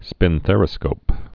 (spĭn-thărĭ-skōp)